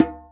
SingleHit_QAS10784.WAV